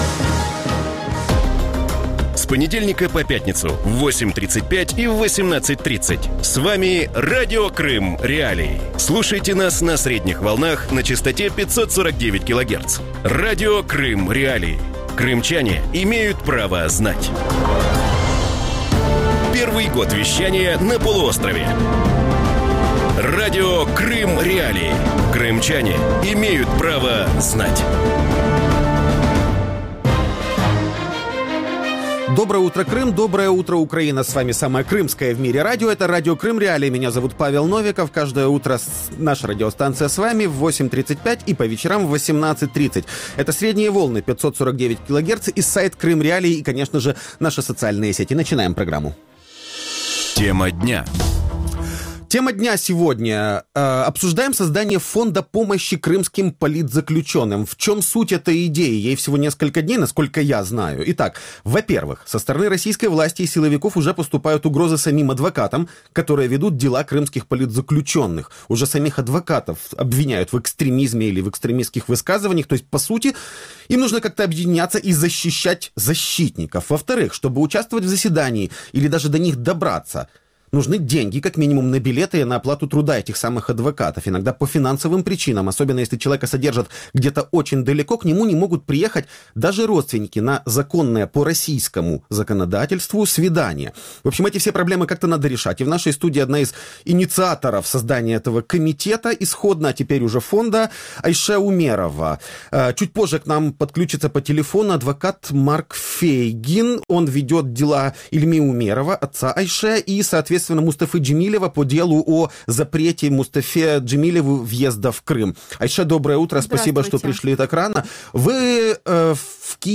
Фонд допомоги кримським політв'язням, над створенням якого зараз працюють у Києві, не зможе повноцінно функціонувати на території Росії, оскільки російська влада намагається задавити будь-яке інакомислення. Таку думку в ранковому ефірі Радіо Крим.Реалії висловив російський адвокат Марк Фейгін....